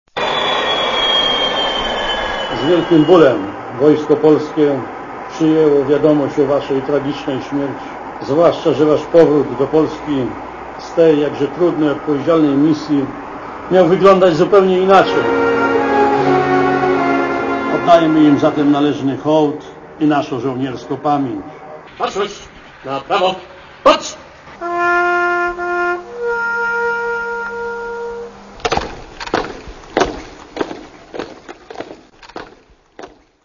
Samolot wylądował wieczorem na lotnisku w Katowicach-Pyrzowicach.